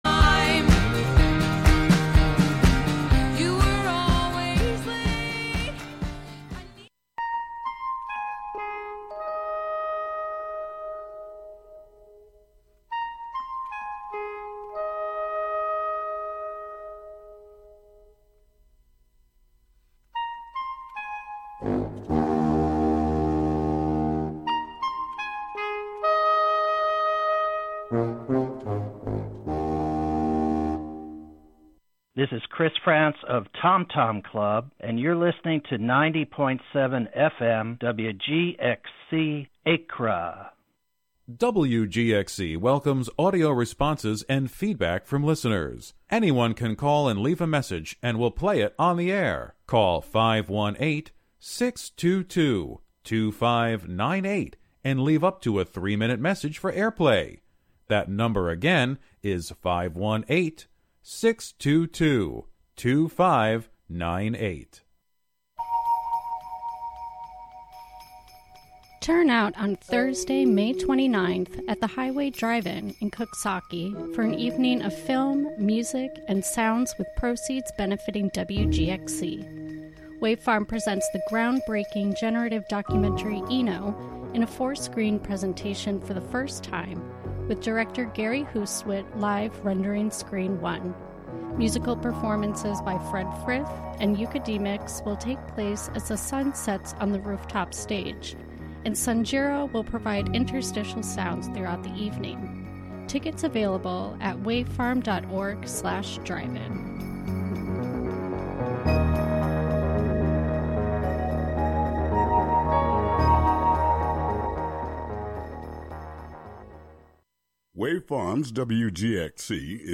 During this show we explore jump from continent to continent to listen to some beautiful examples of singing that doesn't rely on words or language, but uses vocal sounds, syllables, or tones to convey emotion, rhythm or melody.